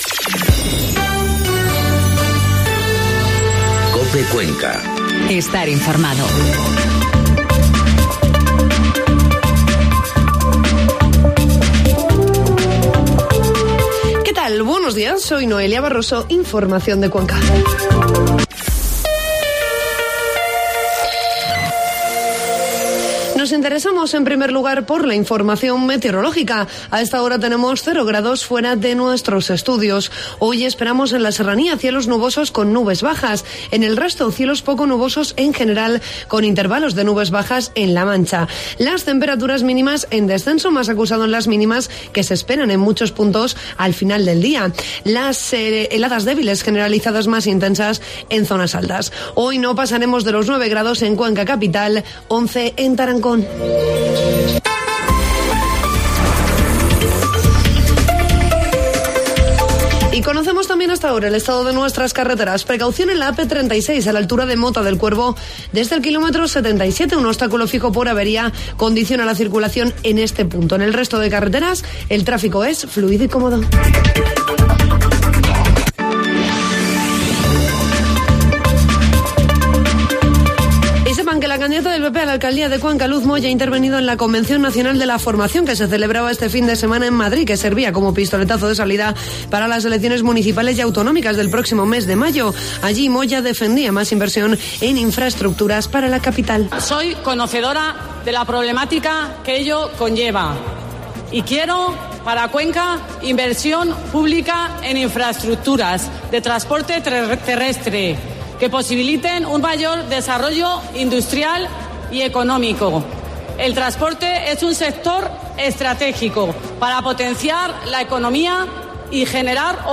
Informativo matinal COPE Cuenca 21 de enero